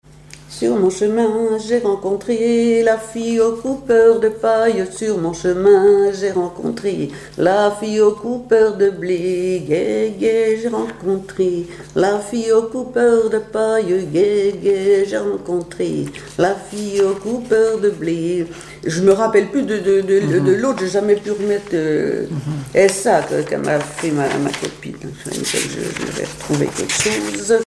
Couplets à danser
Cantiques, chants paillards et chansons
Pièce musicale inédite